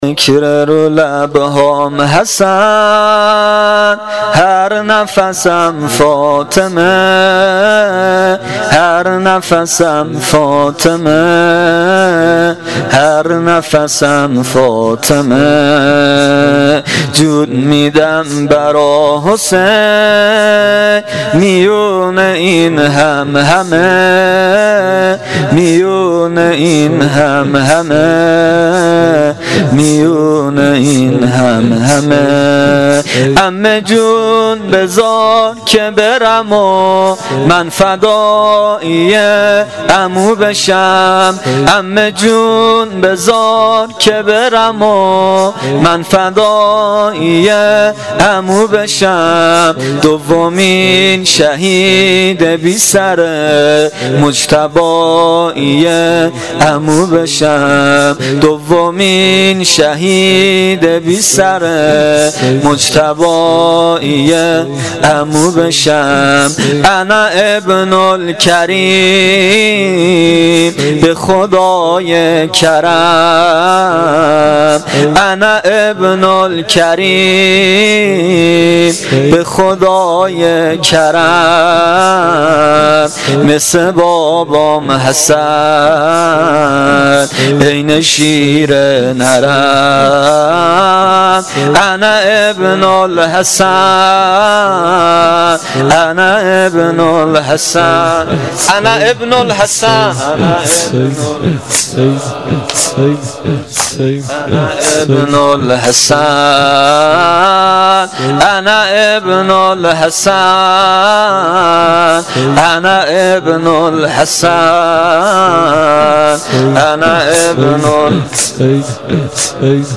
زمینه شب پنجم محرم الحرام 1396